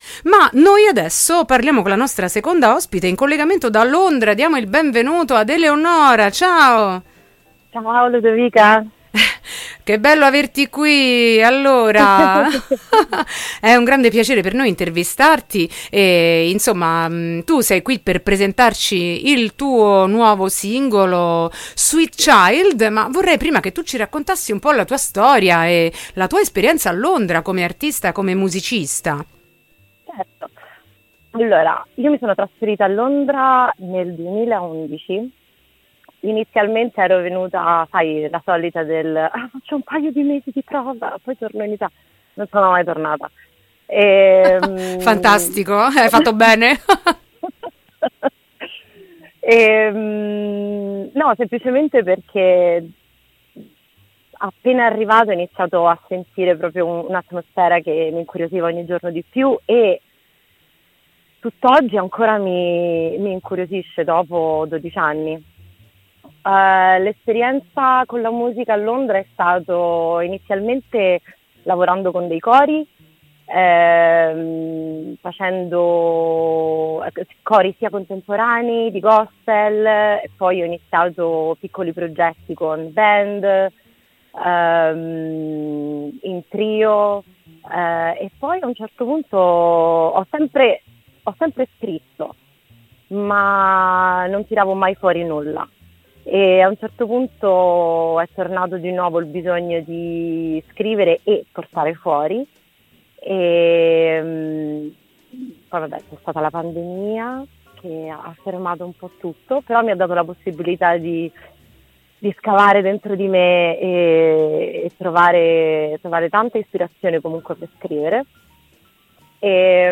Esce “Sweet Child”: intervista